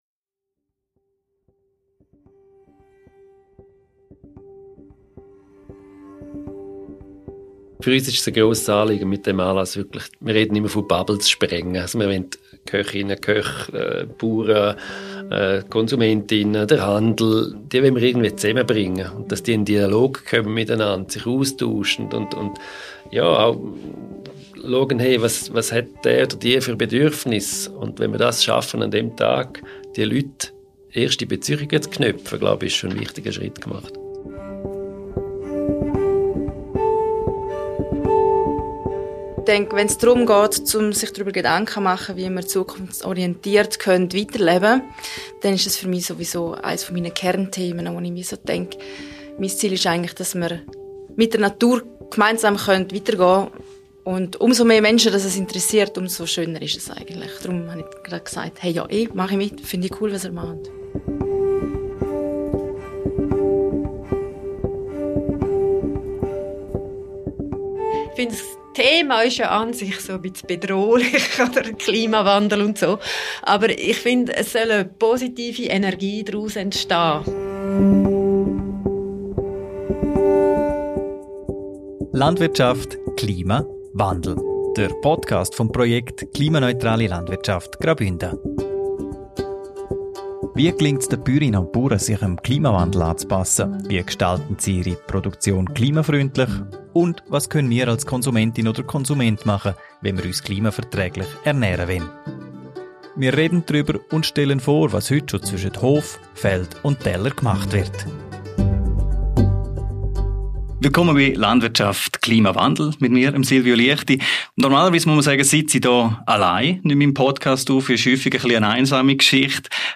E15 Ein Klimagipfel für alle - Talk